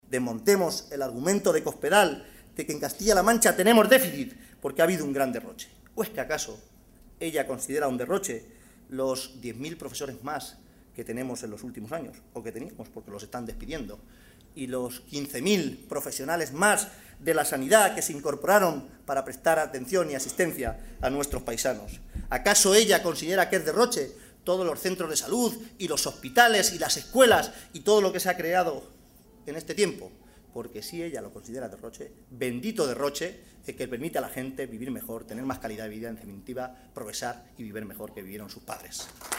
Audio Caballero-intervencion 3
caballerointervencion3.mp3